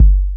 cch_01_kick_one_shot_low_deep_sub.wav